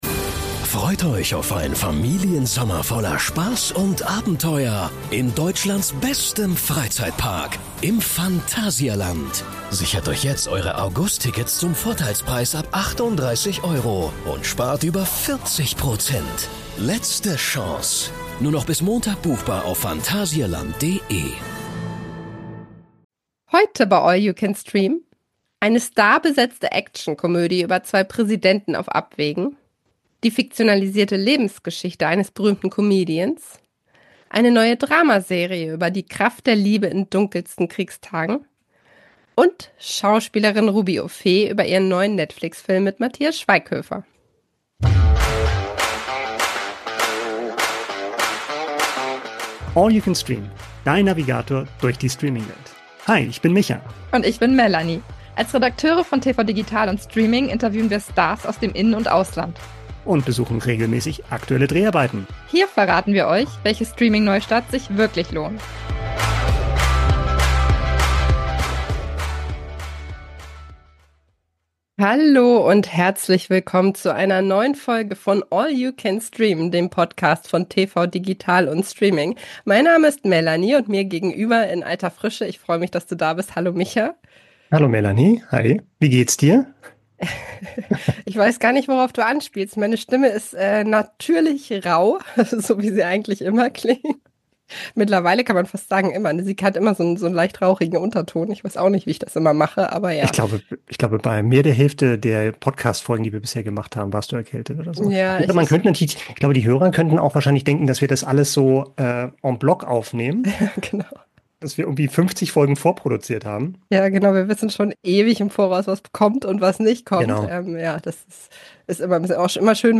Exklusives Interview mit Fahri Yardim